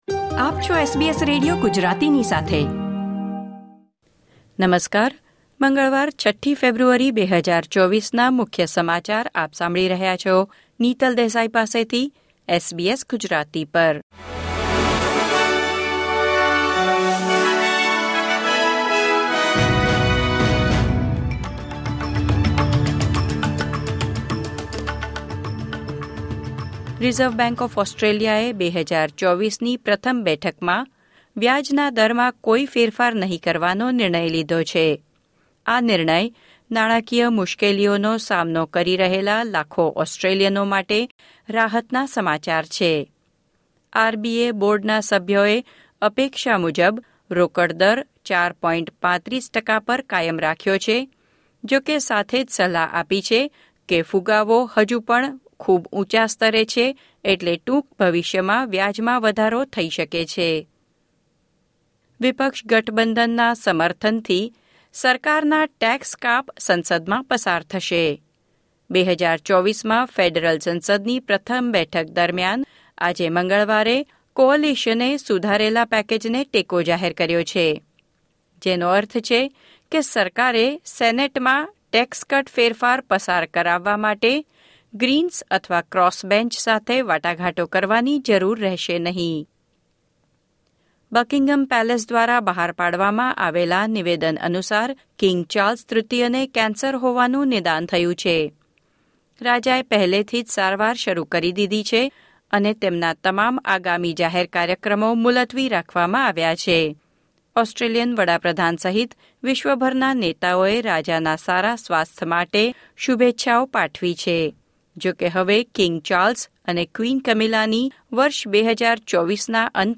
SBS Gujarati News Bulletin 6 February 2024